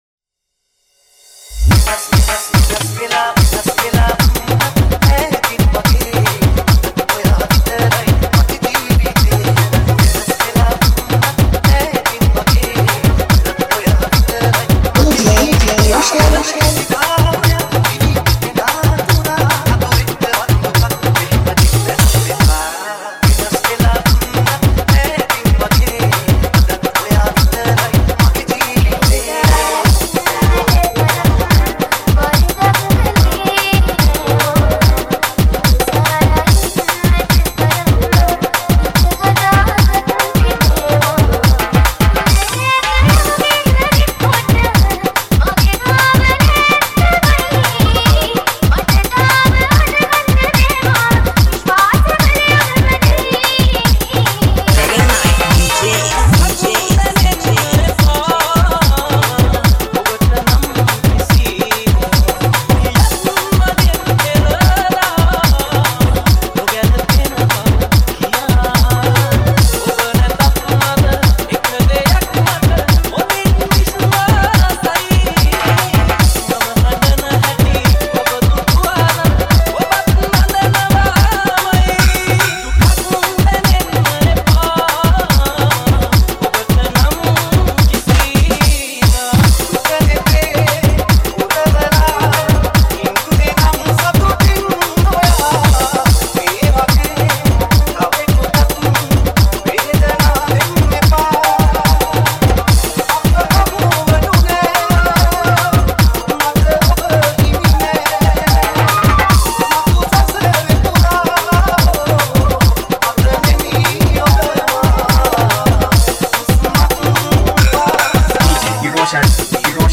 Dj Nonstop